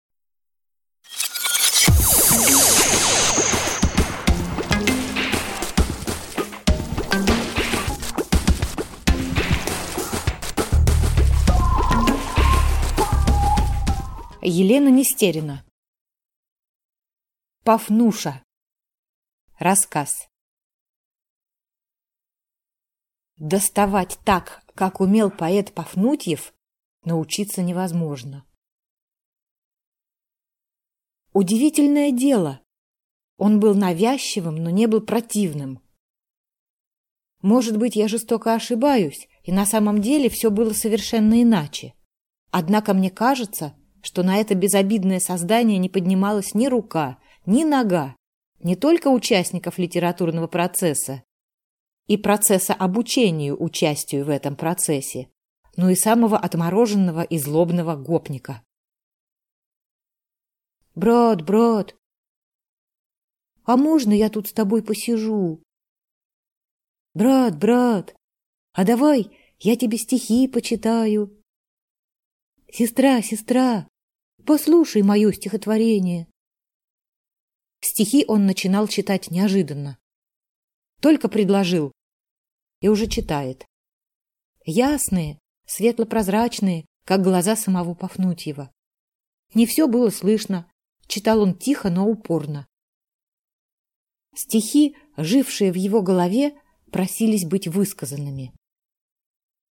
Aудиокнига Пафнуша